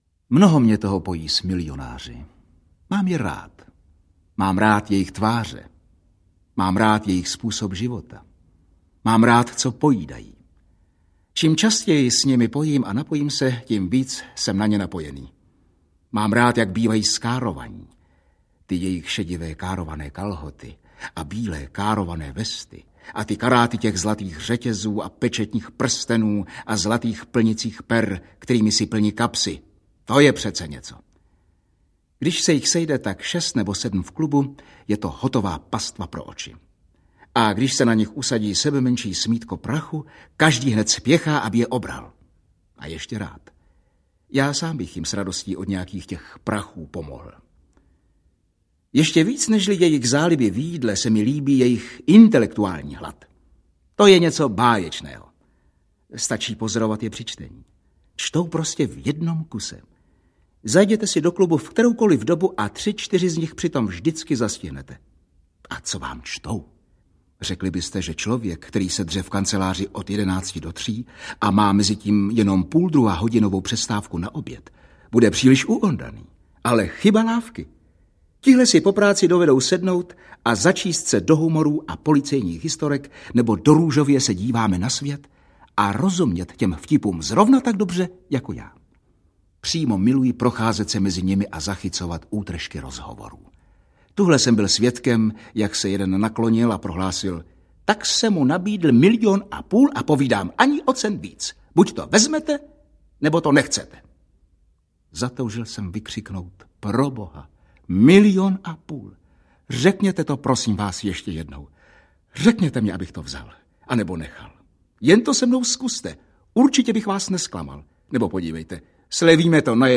Vánoce Smolíka McFiggina a jiné povídky audiokniha
Ukázka z knihy
Je třeba vyzdvihnout také skvělou interpretaci obou herců: Františka Němce a Gabriely Vránové!
• InterpretFrantišek Němec, Gabriela Vránová